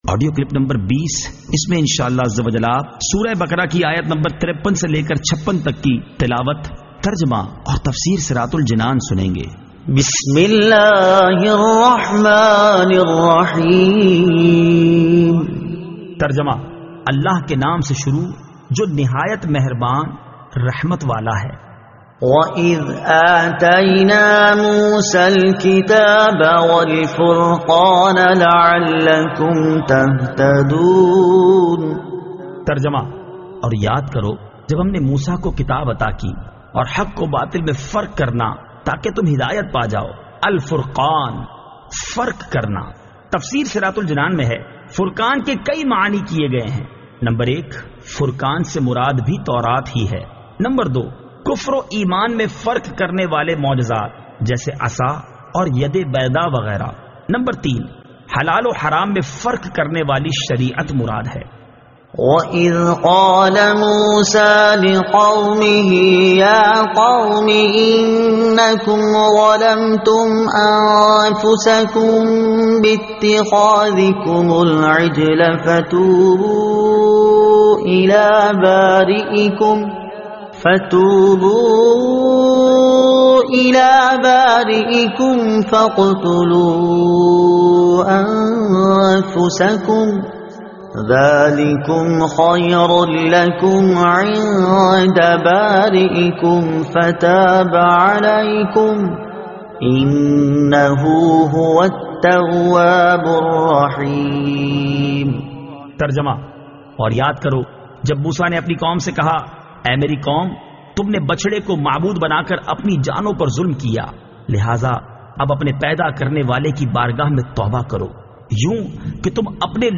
Surah Al-Baqara Ayat 53 To 56 Tilawat , Tarjuma , Tafseer